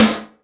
SNARE 006.wav